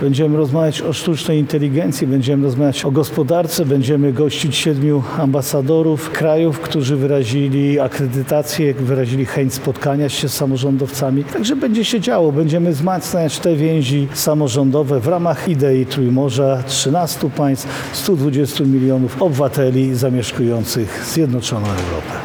– Tematów jest wiele – mówi marszałek województwa lubelskiego Jarosław Stawiarski.